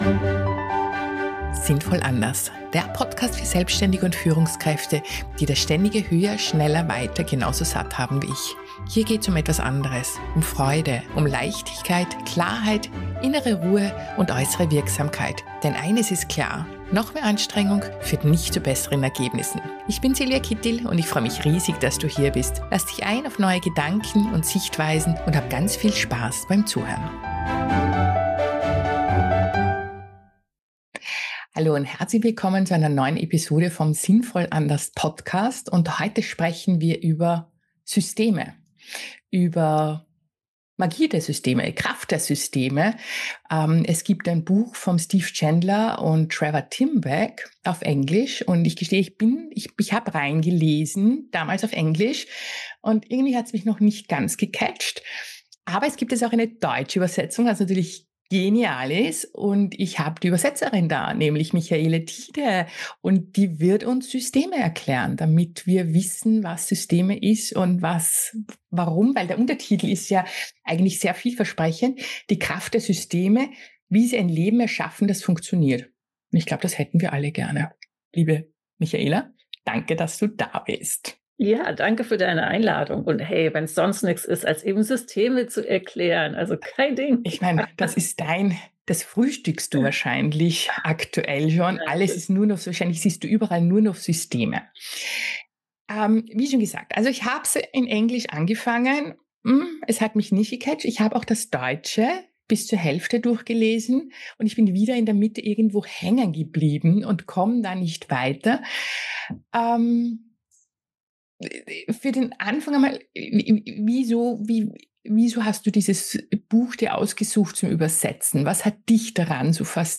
Das Gespräch hat meine Neugier auf das Buch neu geweckt.